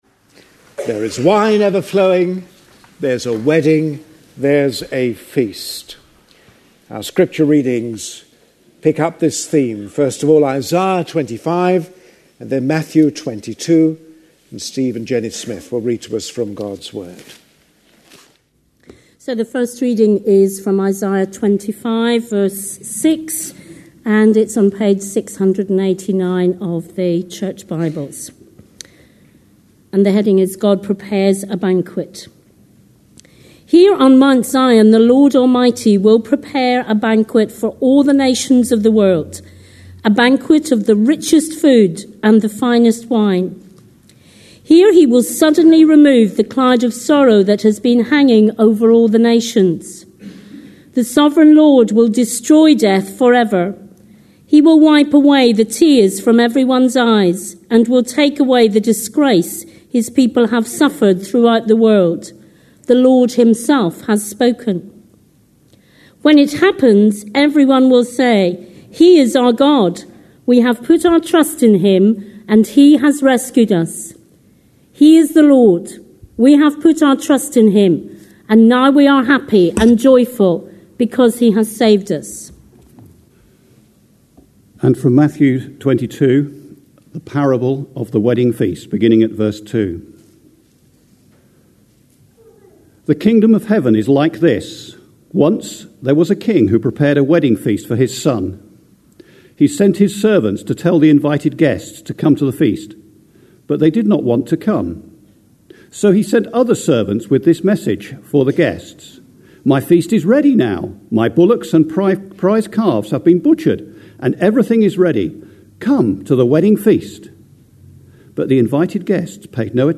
A sermon preached on 31st October, 2010, as part of our Parables of Matthew series.